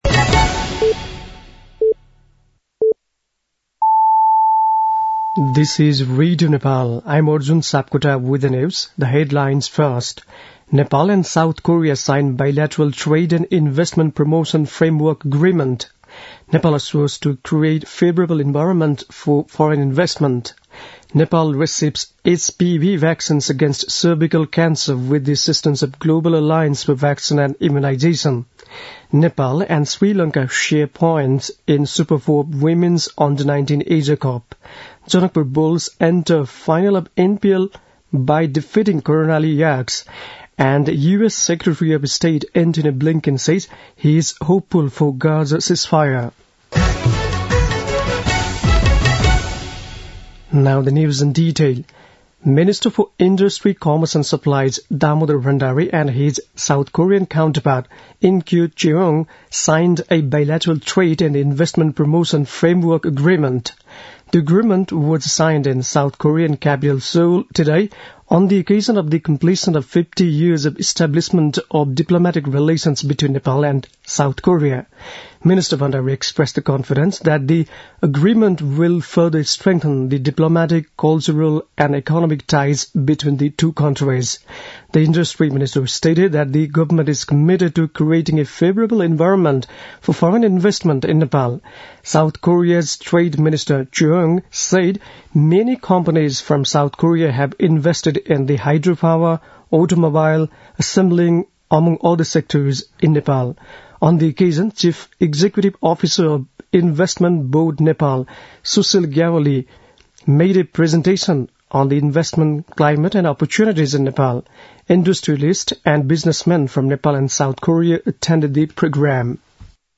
An online outlet of Nepal's national radio broadcaster
बेलुकी ८ बजेको अङ्ग्रेजी समाचार : ५ पुष , २०८१